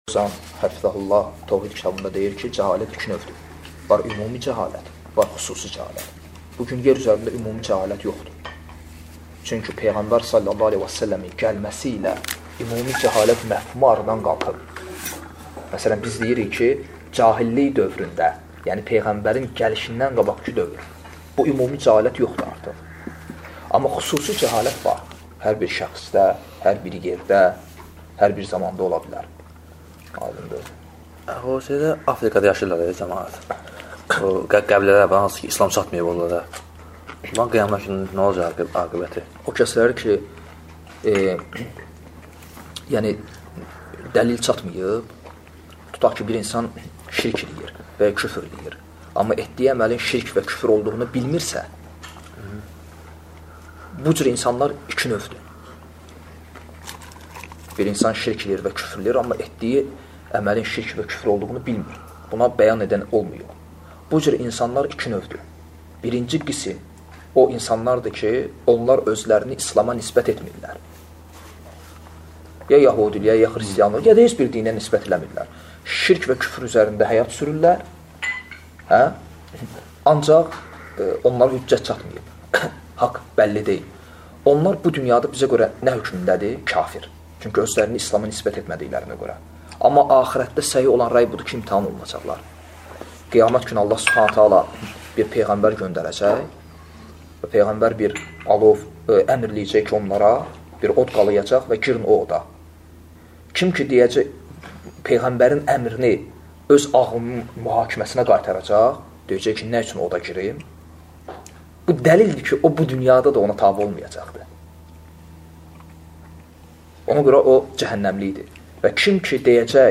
Dərslərdən alıntılar – 86 parça